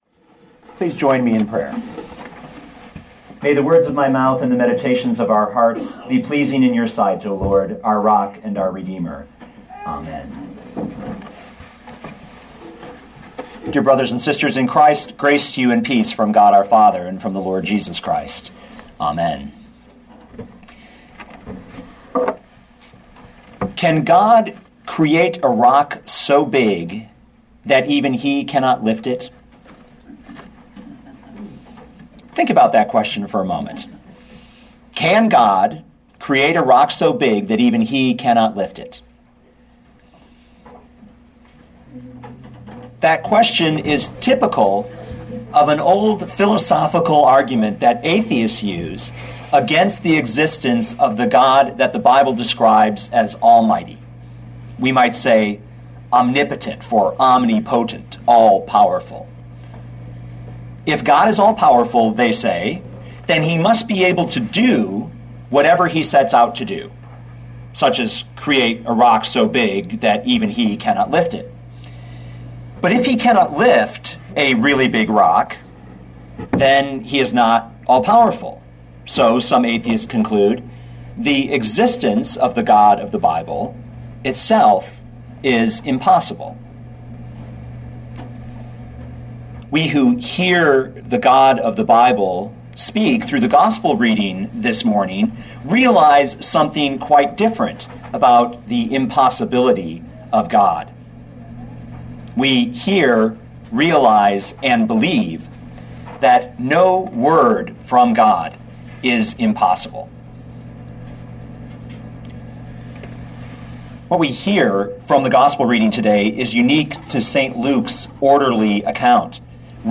2011 Luke 1:26-38 Listen to the sermon with the player below, or, download the audio.